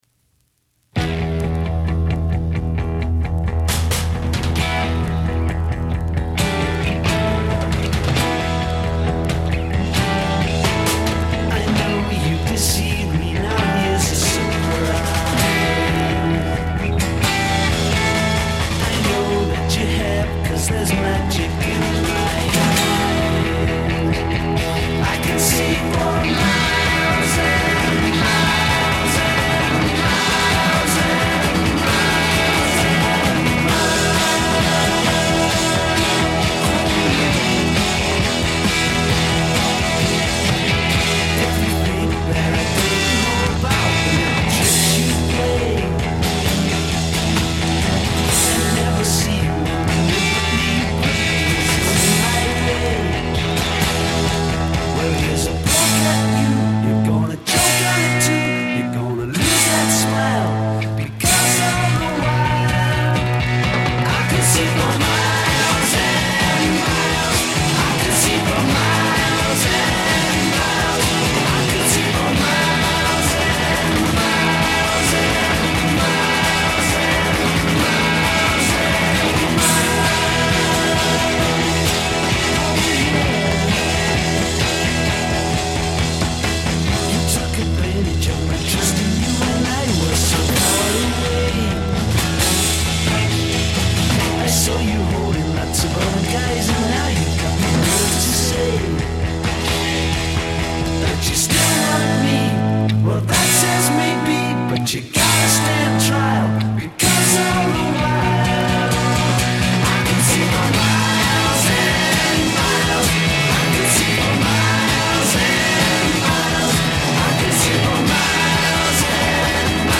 on vinyl